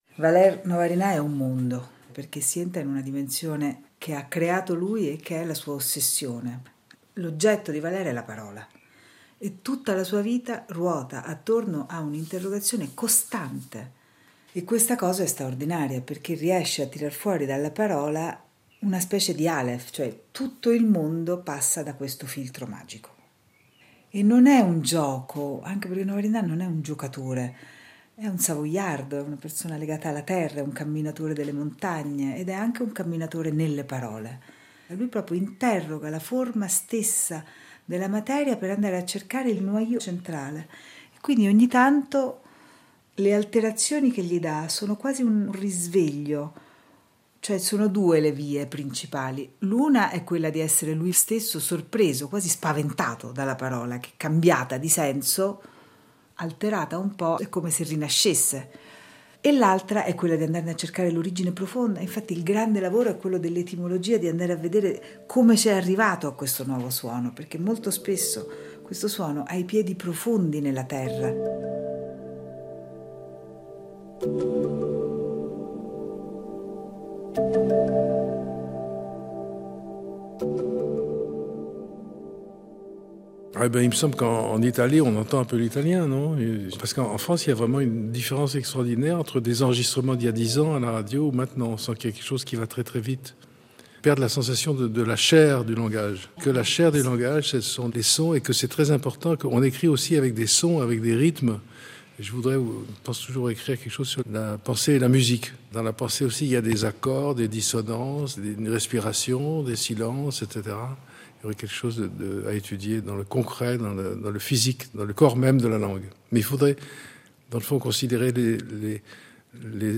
Osserva il cambiamento linguistico contemporaneo, un segno di deperimento fisico, una contrazione della “parola”. Il racconto è cadenzato da alcuni brani tratti da “l’animale del tempo”, nell’interpretazione di Roberto Herlitzka e Andrè Marcon.